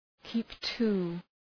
Προφορά